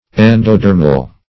Meaning of endodermal. endodermal synonyms, pronunciation, spelling and more from Free Dictionary.
Search Result for " endodermal" : The Collaborative International Dictionary of English v.0.48: Endodermal \En`do*der"mal\, Endodermic \En`do*der"mic\, a. (Biol.)